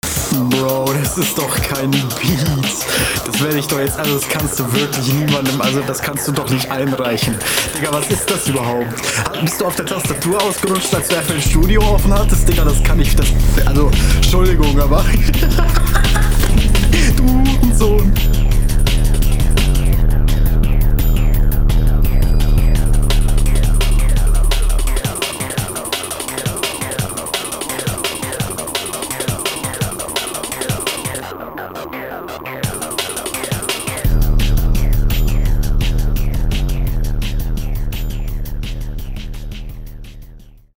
beat ist klar ne katastrophe aber eine zeile hättest du ja bringen könnnen xD